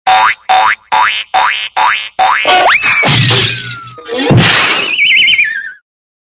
SMS Bonk Sound Effect Free Download
SMS Bonk